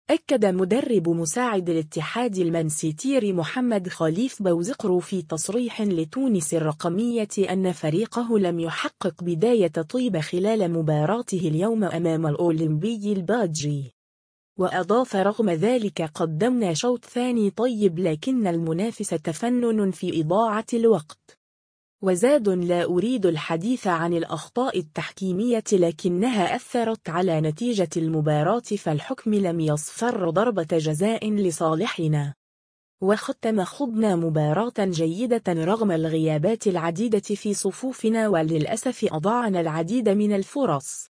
في تصريح لتونس الرقمية